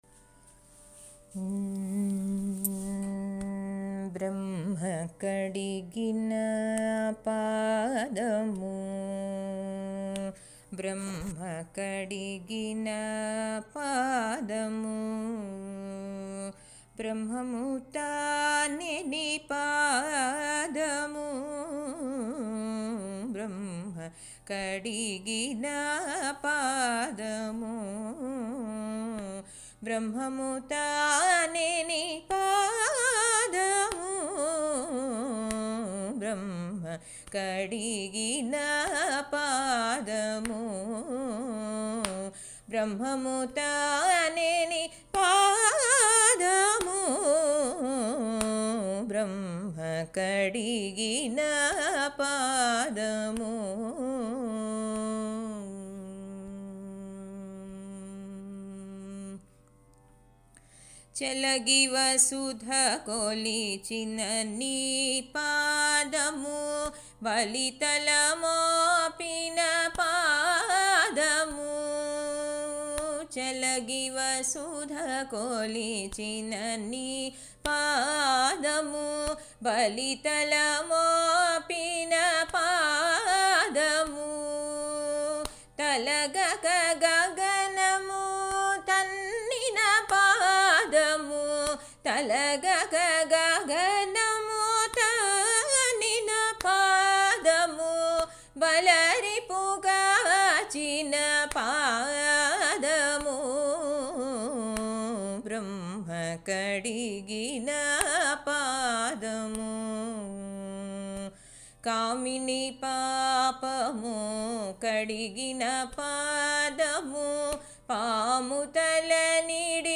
I have been teaching Karnatic classical vocal lessons in Seattle since last 15 years and prior to that I have taught in Bay Area California for about 10 years.
My teaching methods are based on traditional model of teaching ragam, talam , meaning and theory and the class instruction will include slokams, ashtakams, annamacharya krithis, devotional, patriotic songs and bhajans.